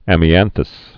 (ămē-ănthəs) also am·i·an·tus (-təs)